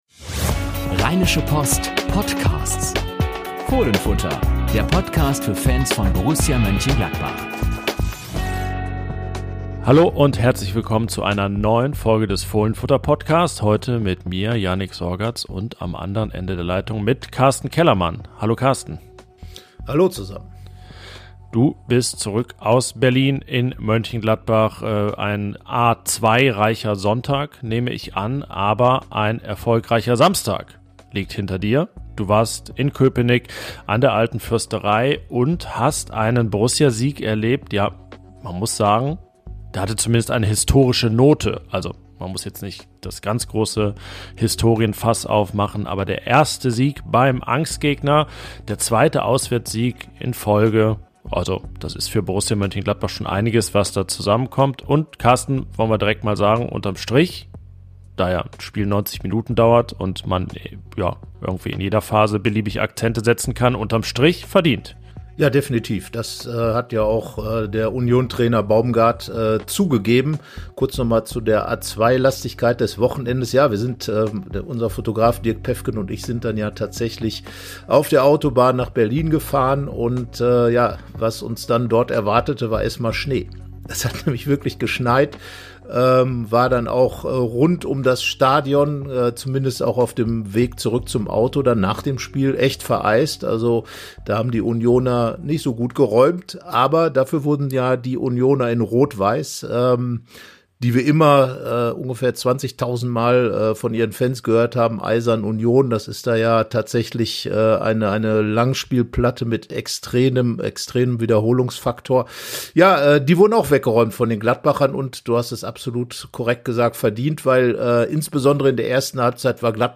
Jede Woche das Neueste zu Borussia – Diskussionen, Analysen und Interviews rund um den Verein.